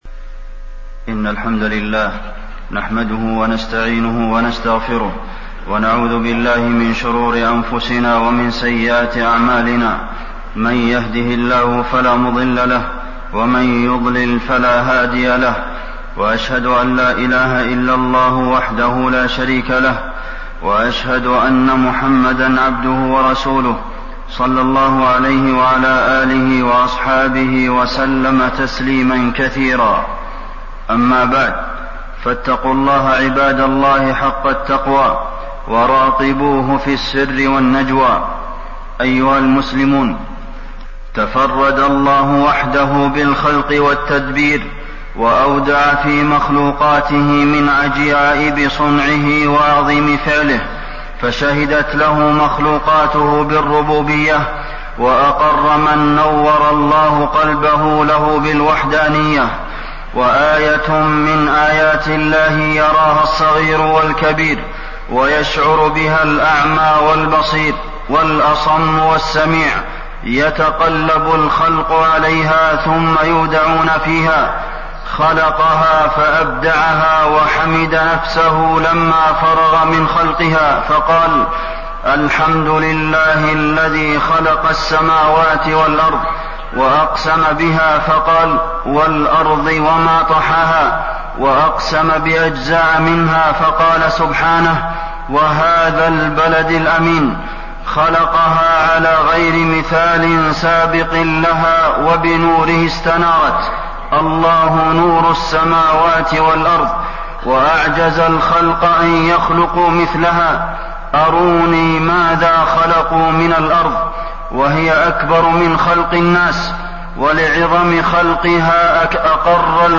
تاريخ النشر ١٠ ربيع الثاني ١٤٣١ هـ المكان: المسجد النبوي الشيخ: فضيلة الشيخ د. عبدالمحسن بن محمد القاسم فضيلة الشيخ د. عبدالمحسن بن محمد القاسم خلق الأرض